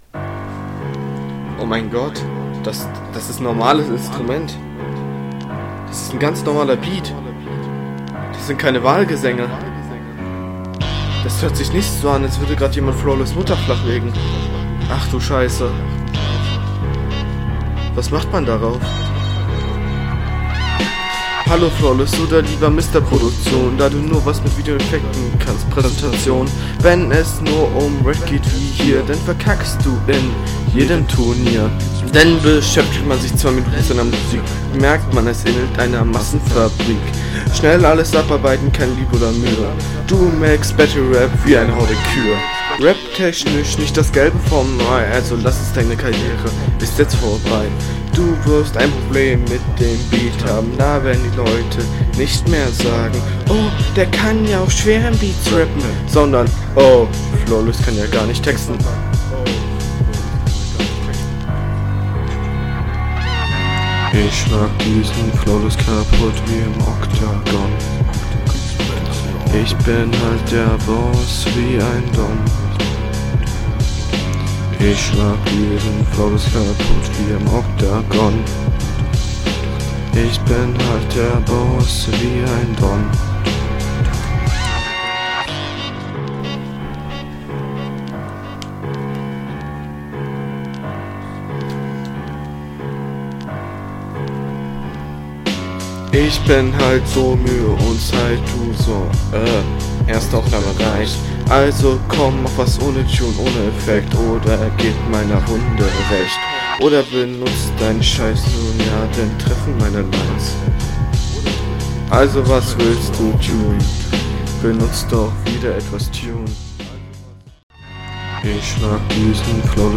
Erstmal ist der Beat way too loud.
Super anstrengend zuhören.